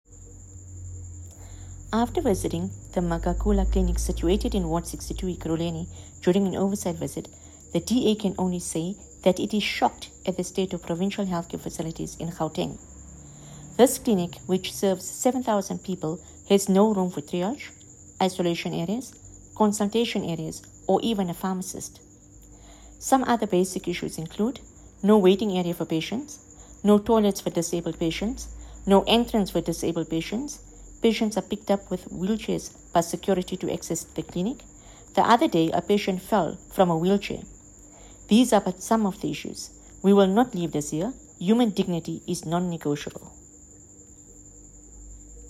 Note to Editors: Please find English and Afrikaans soundbites by Haseena Ismail MP